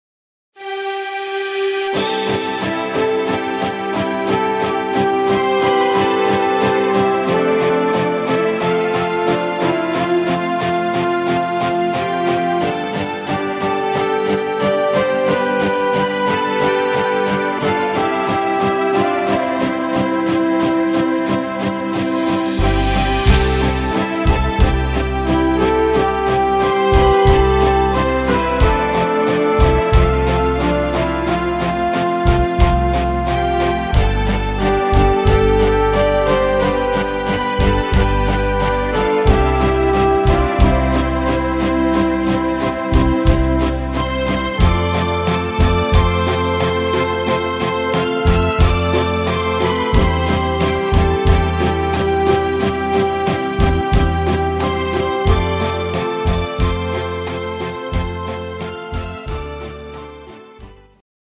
instr. Orchester